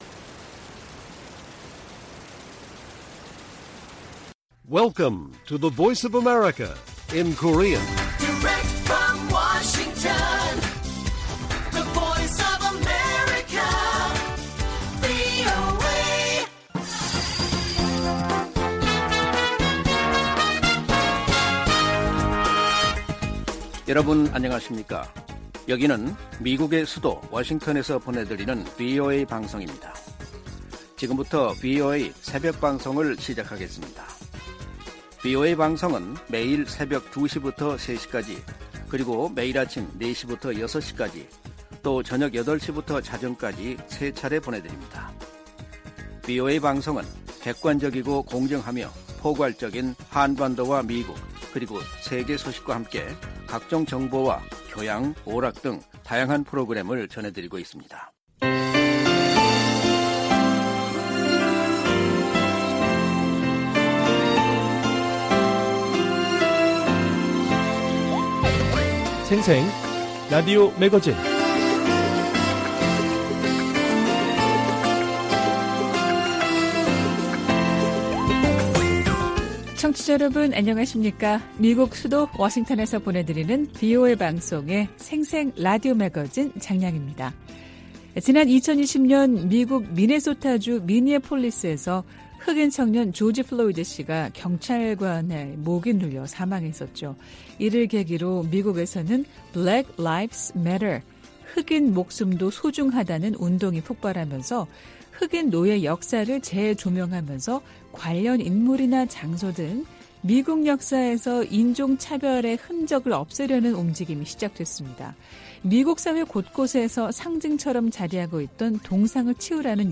VOA 한국어 방송의 월요일 새벽 방송입니다. 한반도 시간 오전 2:00 부터 3:00 까지 방송됩니다.